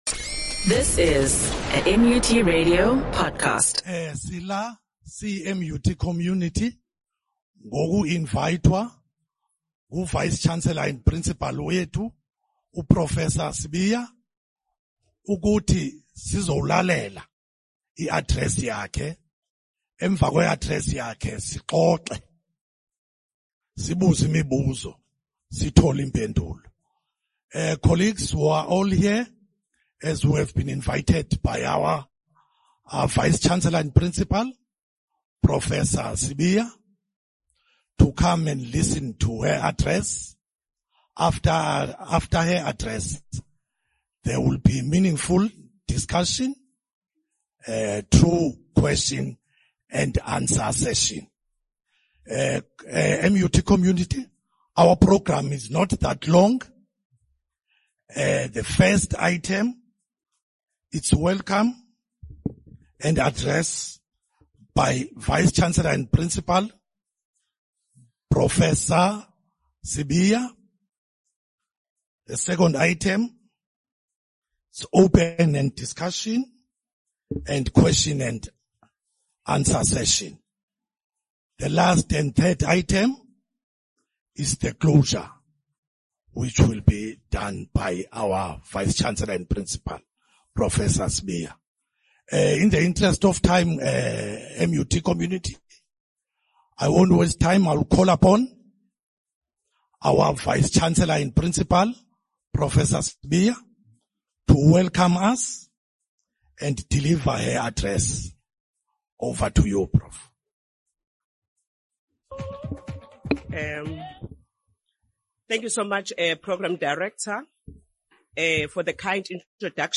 staff members and student leaders